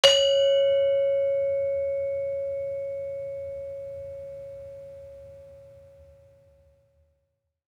Saron-1-C#4-f.wav